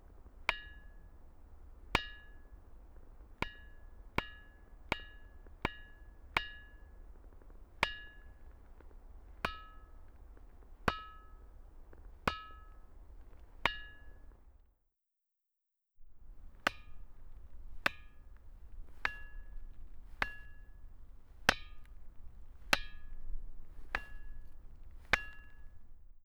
Type: Klangstein
Den gir to–tre ulike toner.
Begge steder er det to tydelige toner ettersom hvor man slår. Den lyseste frekvensen er tydelig begge steder (ca. G#), mens en mørkere tone (rundt henholdsvis E og Eb) er litt mindre klar.